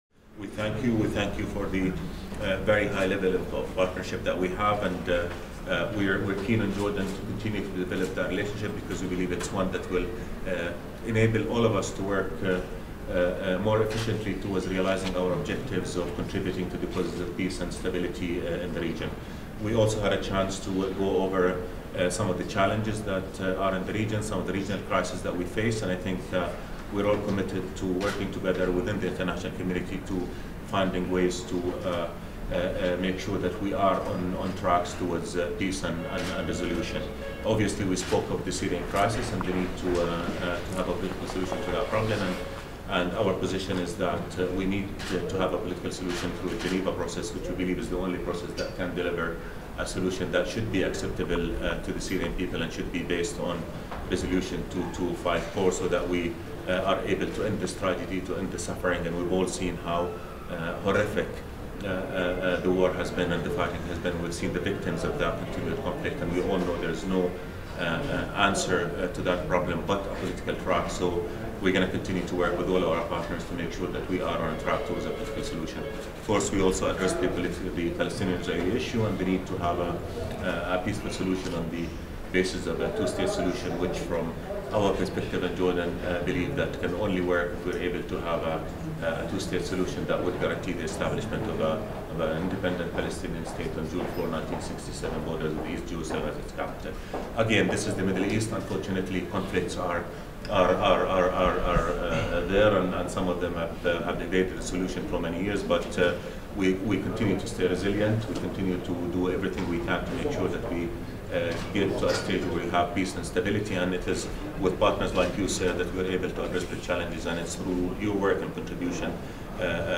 ORIGINAL - Joint press statements by NATO Secretary General Jens Stoltenberg and the Minister of Foreign Affairs of Jordan, Ayman Safadi 06 Mar. 2018 | download mp3 ORIGINAL - Press point by NATO Secretary General Jens Stoltenberg at the occasion of his visit to Jordan 06 Mar. 2018 | download mp3